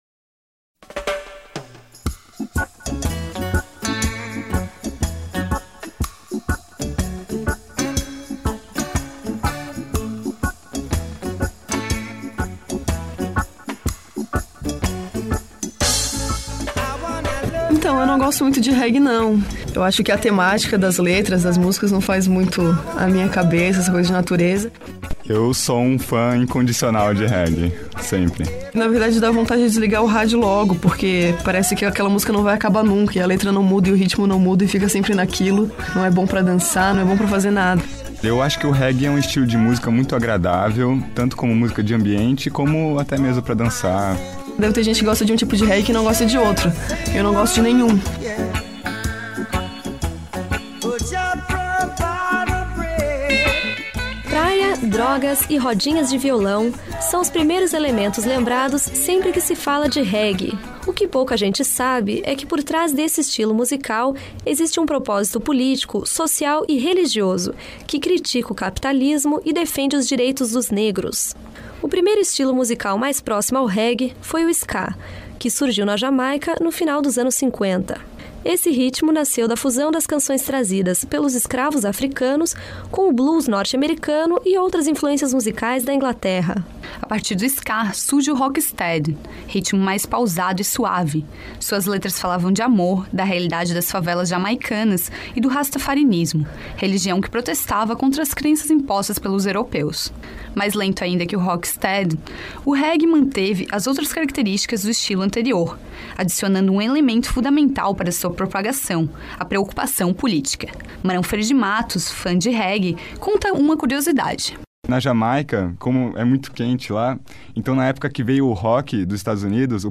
O ritmo jamaicano é o tema deste documentário